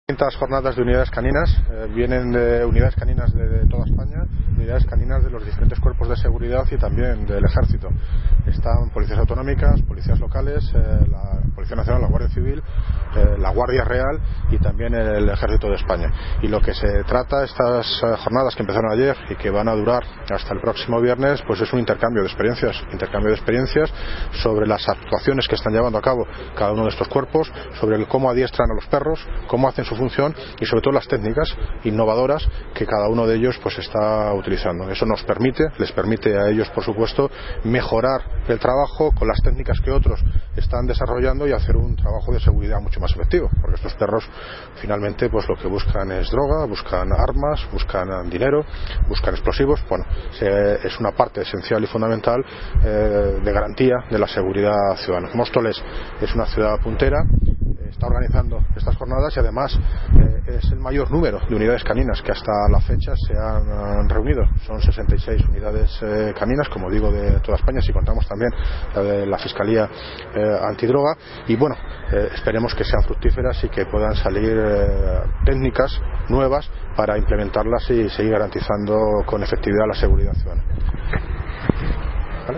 Audio - David Lucas (Alcalde de Móstoles) Sobre JORNADAS CANINAS